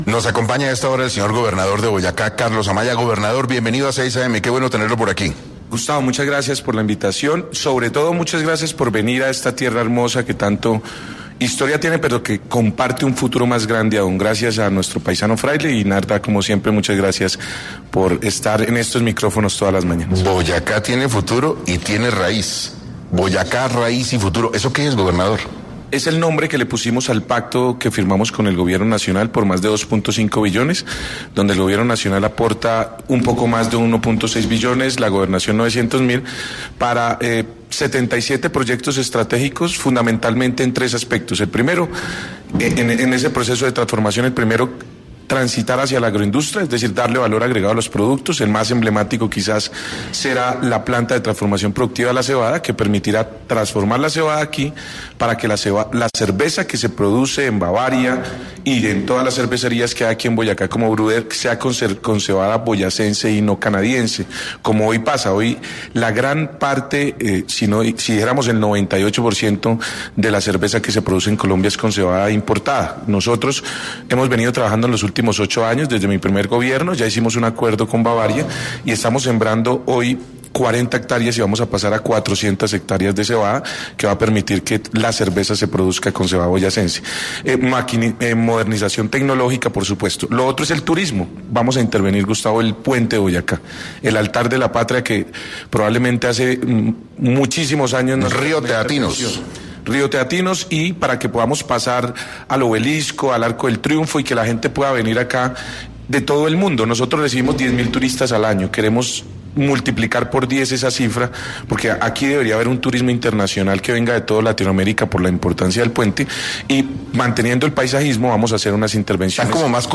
En Caracol Radio estuvo Carlos Amaya, gobernador de Boyacá, explicando las inversiones que se desarrollarán dentro de poco.